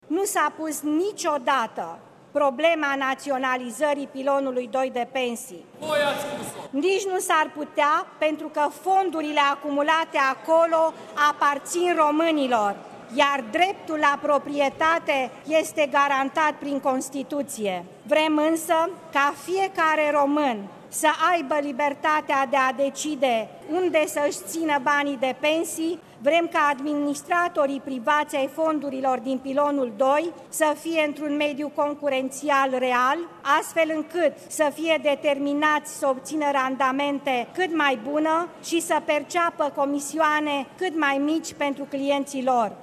Premierul Viorica Dăncilă a anunțat de la tribuna Parlamentului, că Guvernul nu va desființa Pilonul II de pensii, însă românii vor avea mai multe opțiuni atunci când vor contribui la acest fond.